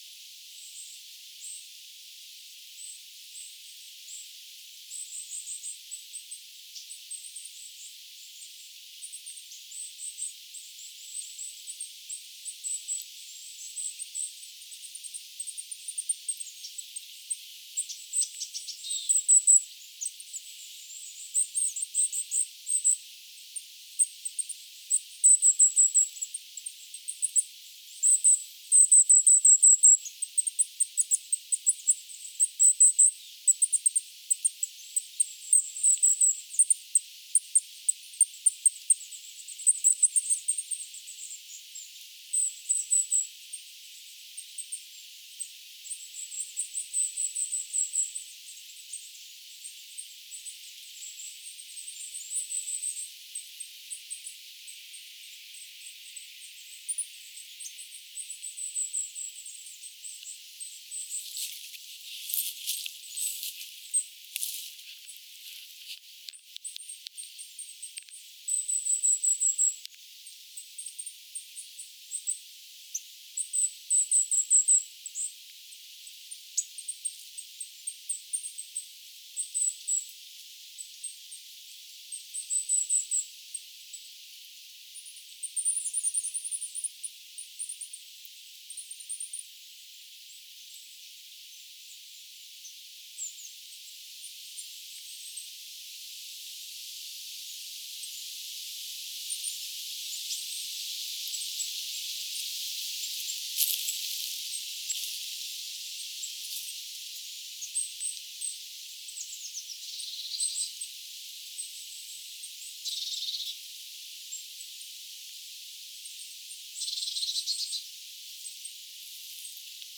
neljä hippiäistä käy tässä uteliaasti
tutustumassa lintuharrastajaan aivan läheltä
nelja_hippiaista_kay_tutkimassa_lintuharrastajaa_lahelta_noin_parin_metrin_paasta.mp3